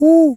pgs/Assets/Audio/Animal_Impersonations/owl_hoot_01.wav
owl_hoot_01.wav